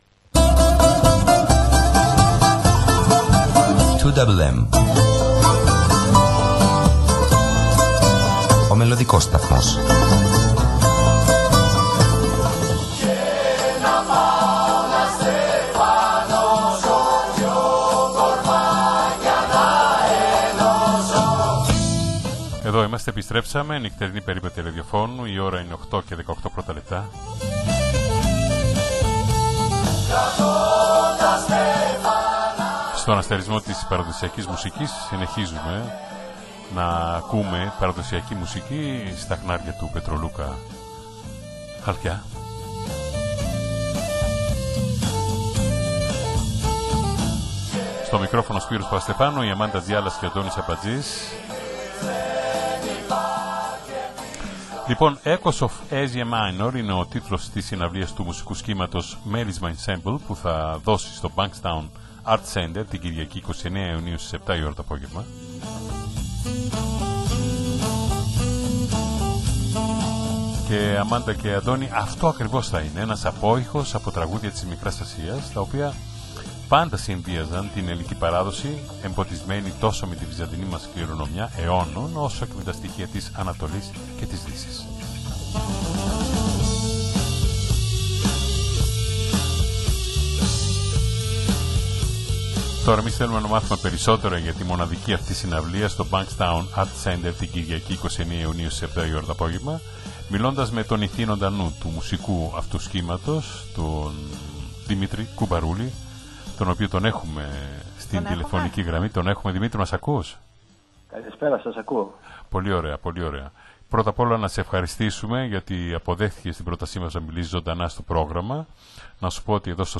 μίλησε ζωντανά